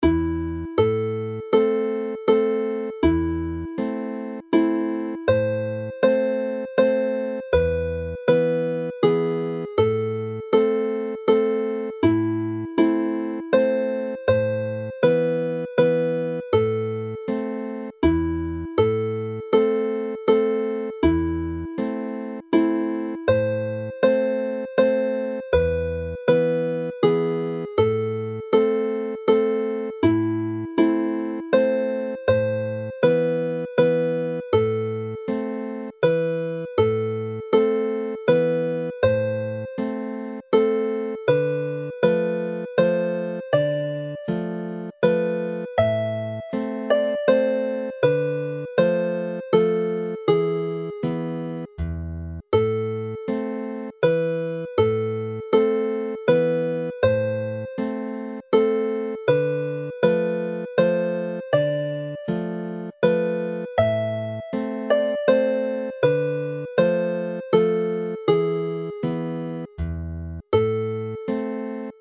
Play the tune slowly